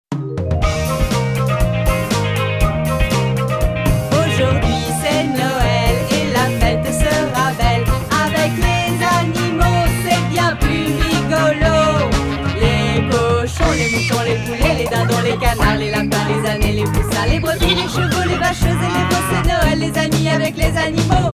En avant première, un extrait des chansons de Bartacus, Lili et Zélectron !